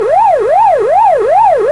Siren